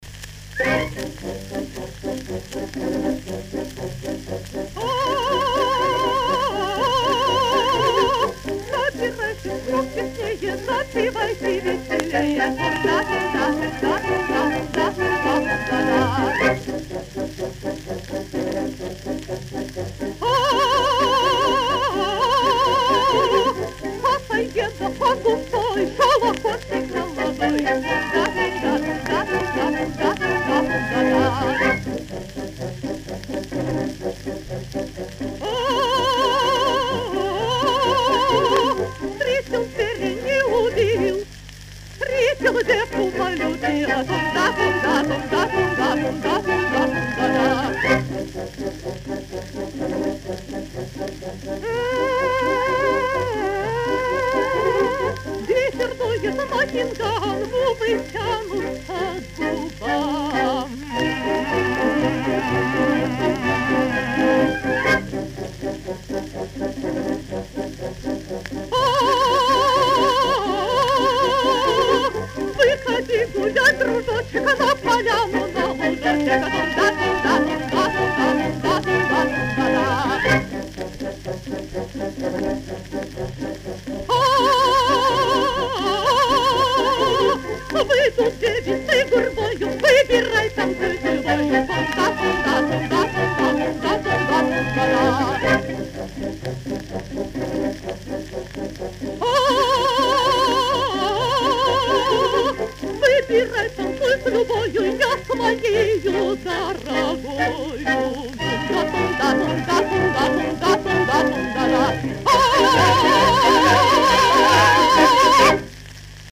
Несколько иное исполнение другой певицей.